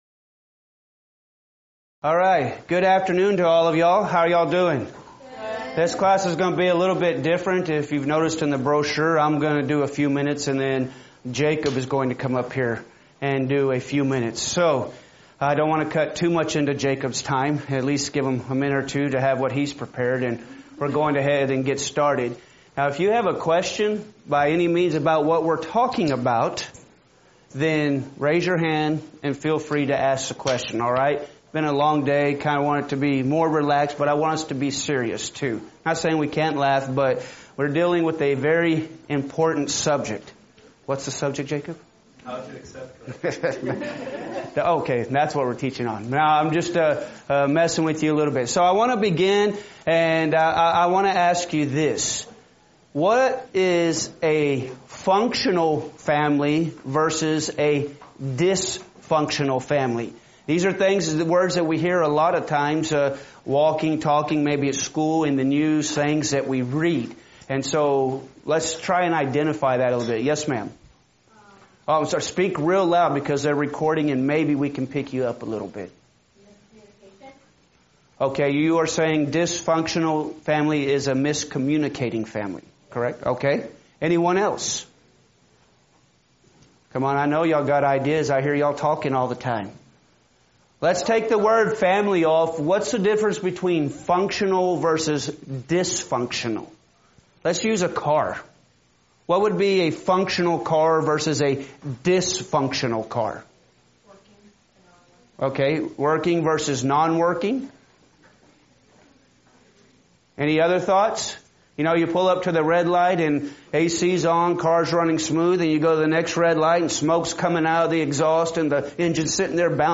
Event: 2018 Focal Point Theme/Title: Preacher's Workshop
lecture